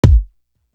Desire Kick.wav